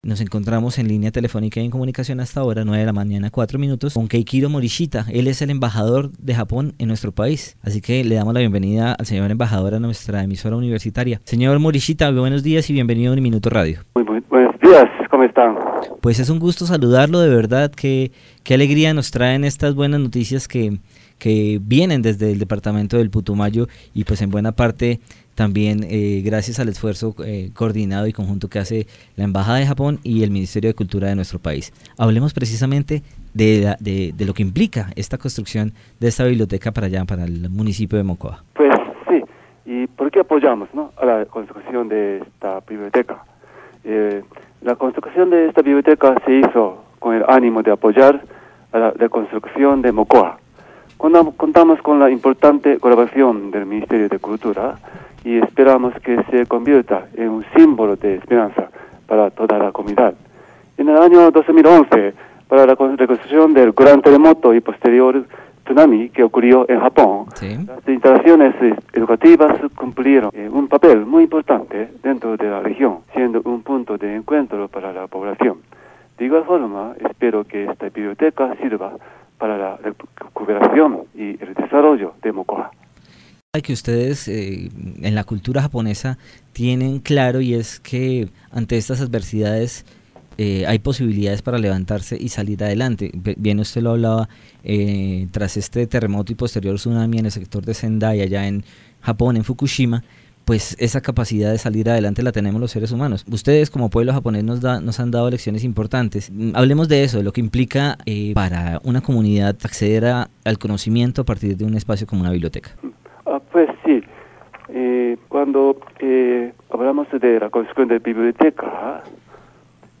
Mocoa conmemoró el primer aniversario de estos terribles hechos, ocasión adecuada para que la Embajada de Japón en Colombia y el Ministerio de Cultura, realizara la entrega de una moderna biblioteca en el sector conocido como los Sauces, cuya inversión ascendió a los $372 millones de pesos. Keiichiro Morishita, embajador de Japón en tierras cafeteras, habló con Aquí y Ahora de UNIMINUTO Radio acerca de este proyecto que ayudará a miles de personas.